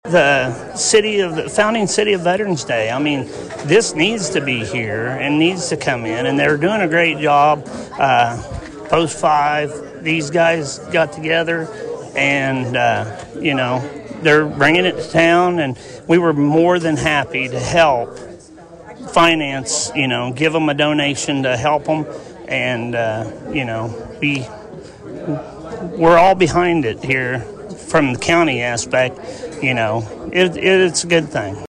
Those sentiments are shared by Lyon County Commission Chair Ken Duft.